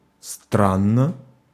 Ääntäminen
US : IPA : ['streɪn.dʒ]